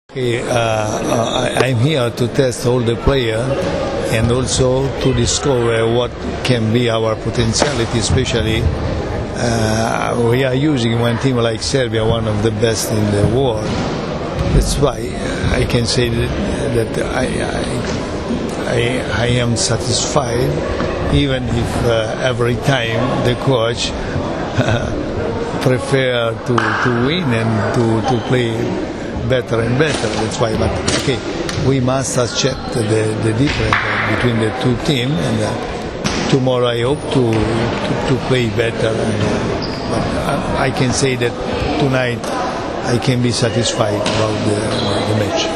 IZJAVA ANTONIJA ĐAKOBE, SELEKTORA EGIPTA